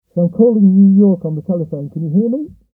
We connected the output from the transformer to a balanced microphone input on a UA Volt 2 interface, and spoke loudly into the diapghragm.
The sound is somewhat muffled and very peaky, but the voices are just about intelligible.
MoMIcs_Bells_Gallow_FirstNewSkin_0.4mmGap.mp3